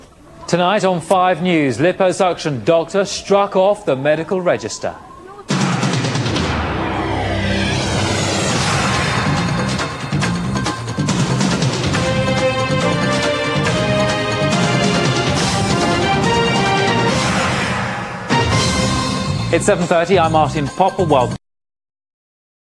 Opening Titles